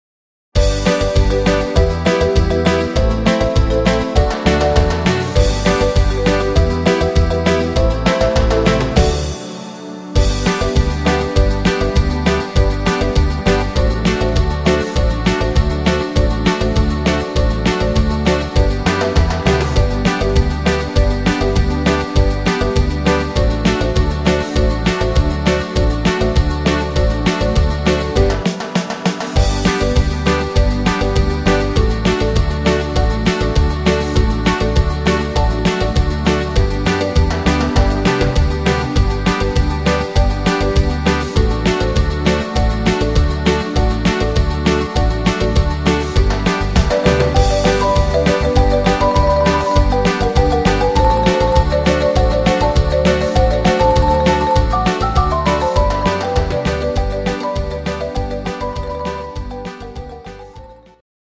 instr. Orgel